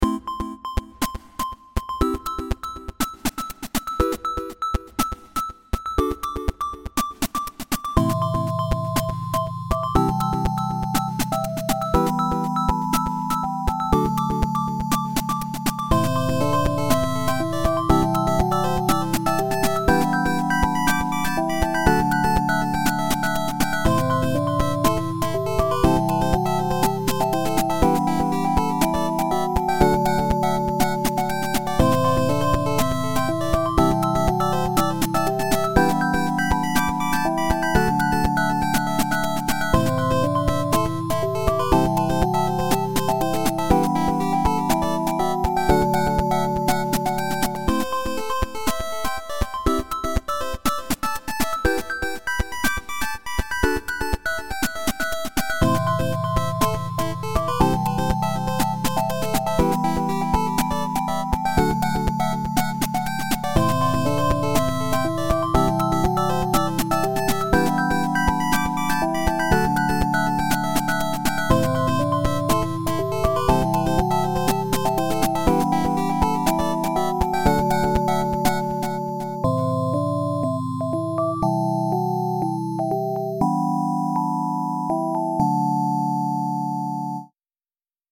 472452_8-bit-ambient..> 2023-01-22 15:17  1.3M